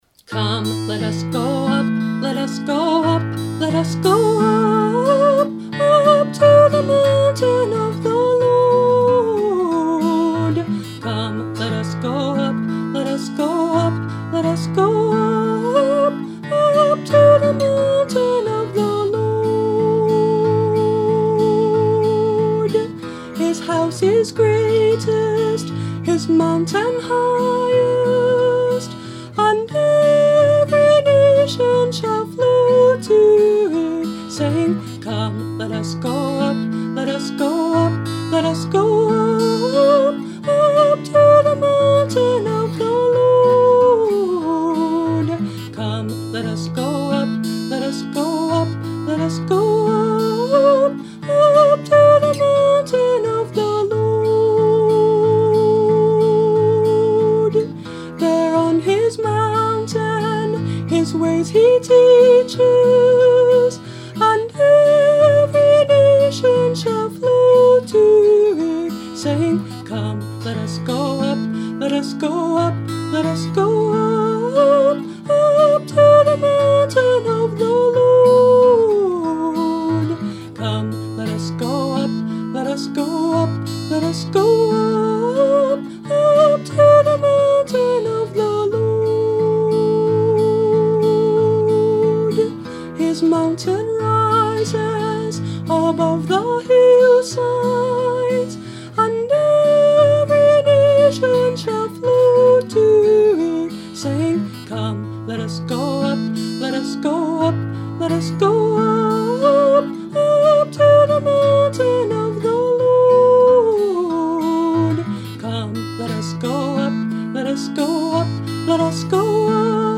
Instrument: Tempo – Seagull Excursion Folk Acoustic Guitar